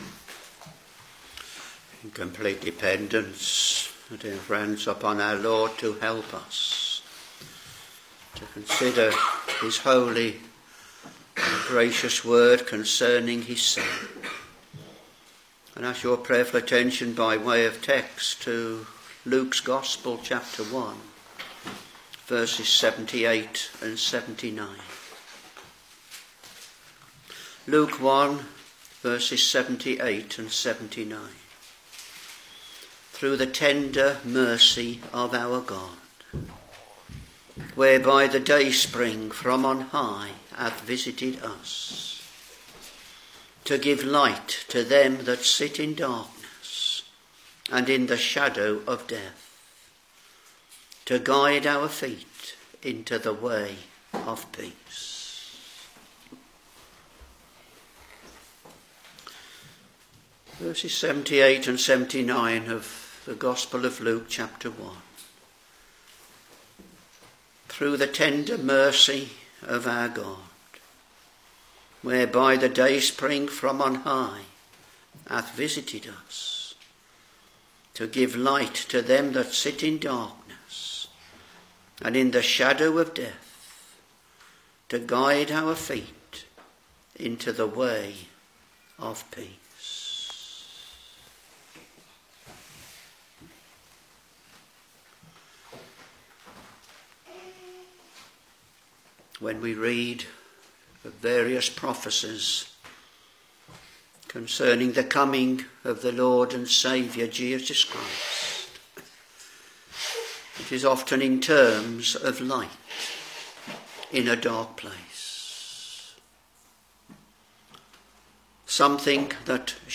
Sunday (AM) 21-DEC-2025: preached